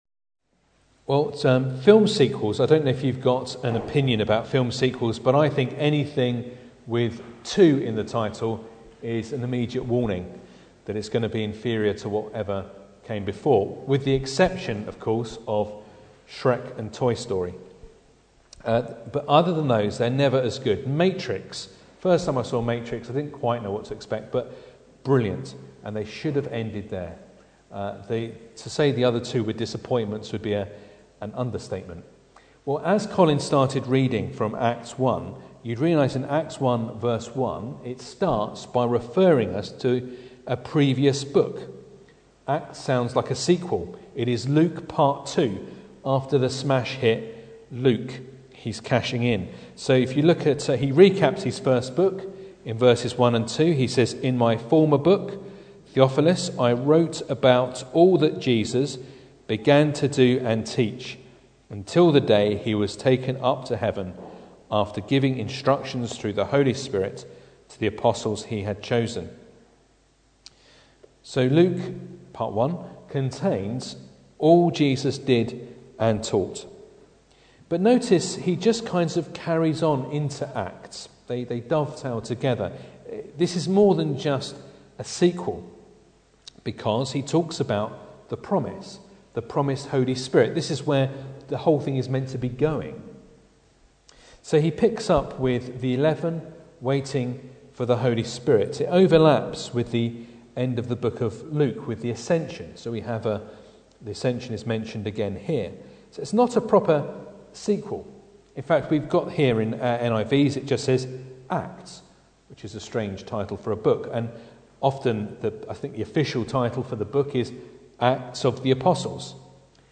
Acts 1:1-11 Service Type: Sunday Evening Bible Text